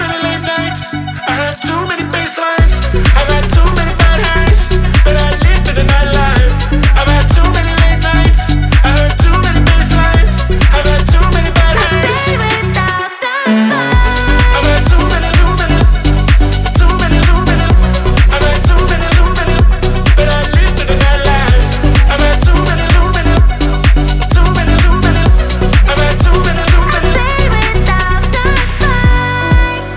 2024-10-25 Жанр: Танцевальные Длительность